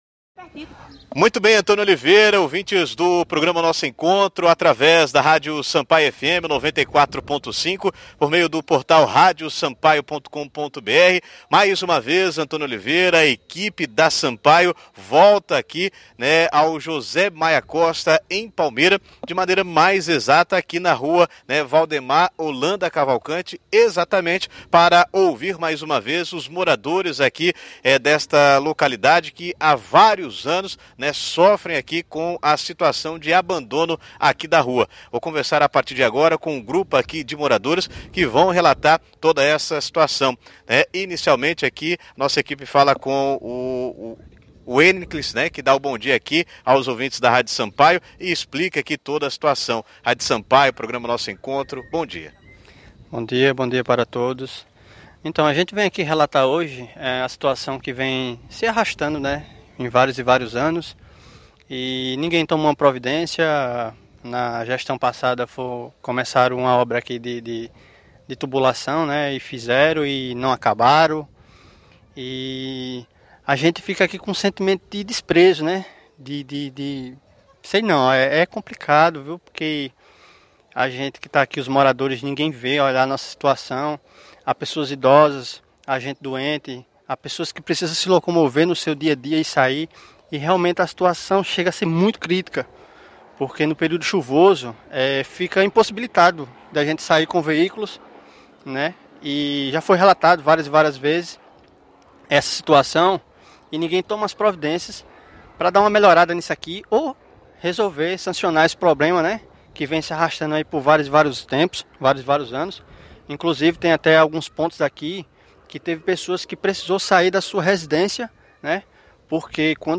Um morador da região, relatou o sentimento de abandono vivido pelos moradores.